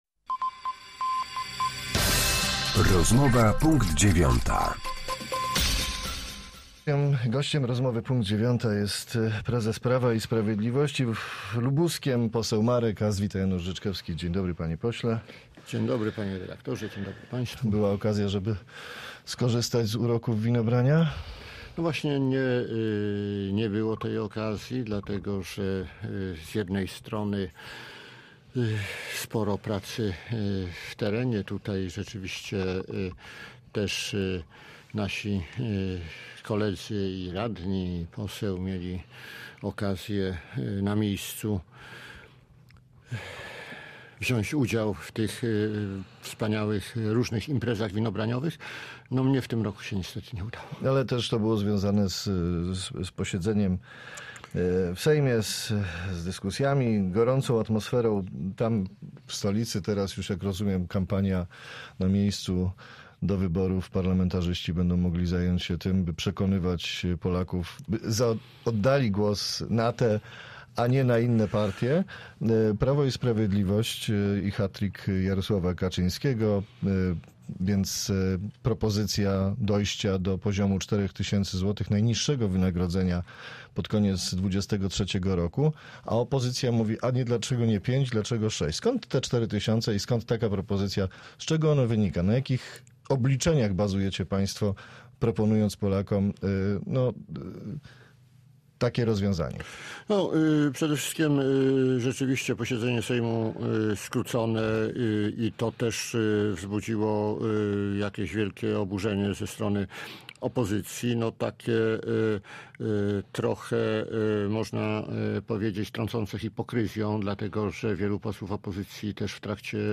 Z przewodniczącym lubuskich struktur PiS rozmawia